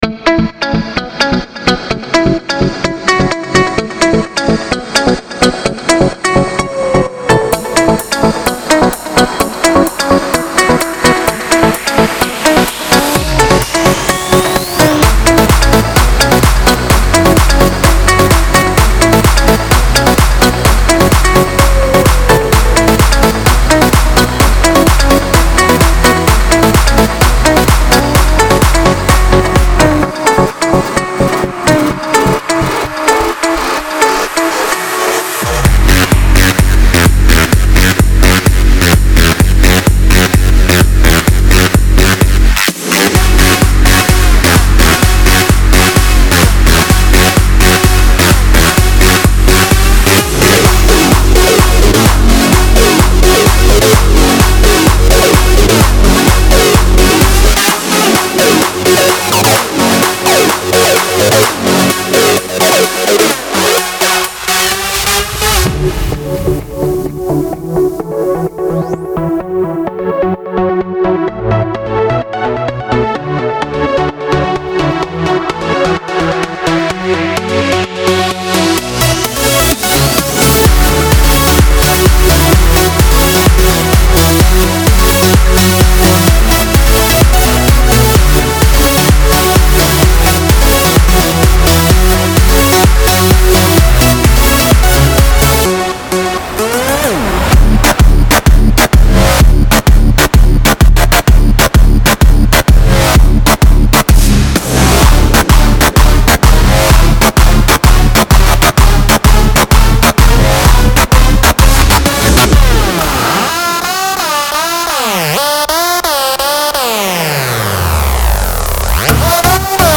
EDMHouse
非常适合EDM，Big Room，Electro House，Progressive House的制作人
•60低音循环
•50个跌落合成器循环
•60个鼓循环
•40个旋律循环